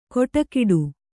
♪ koṭakiḍu